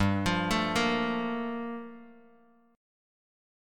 Gm9 Chord
Listen to Gm9 strummed